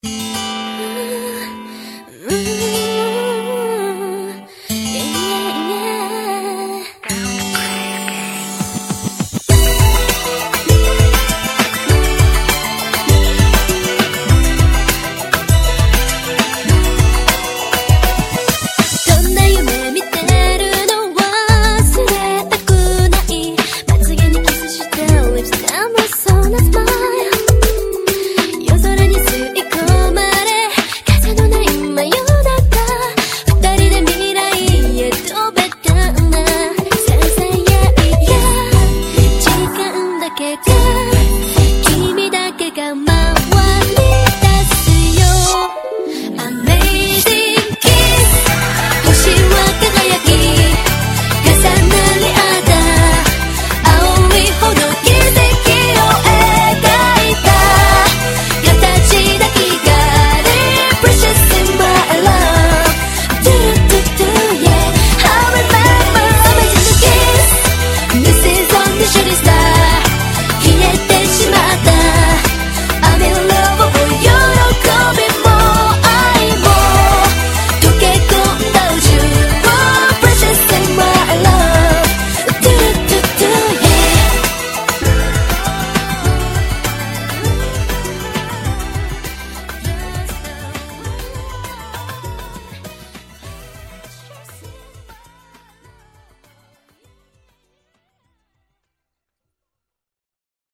BPM100--1
Audio QualityPerfect (High Quality)